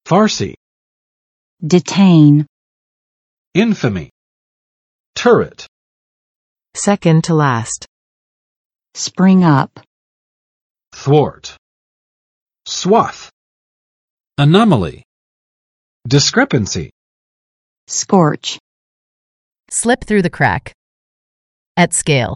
[ˋfɑrsɪ] n. 波斯语